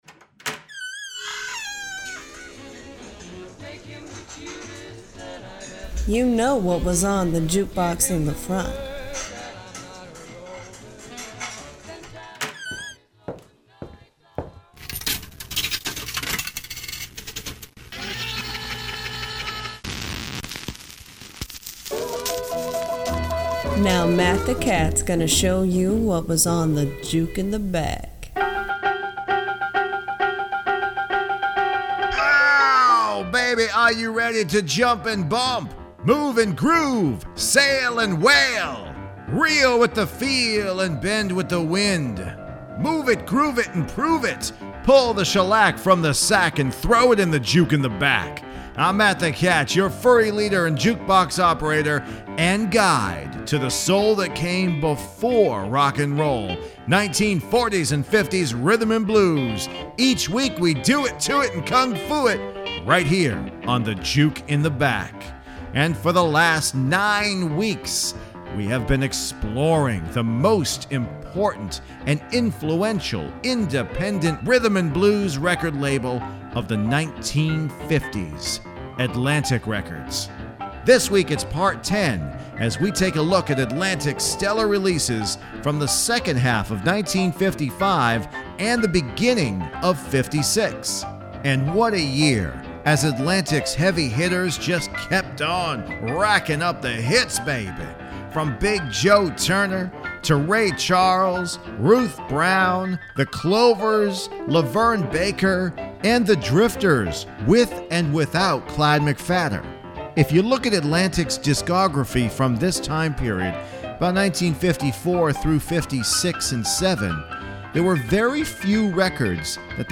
To hear all this great 1950s rhythm & blues, you had to go to “Juke In The Back.”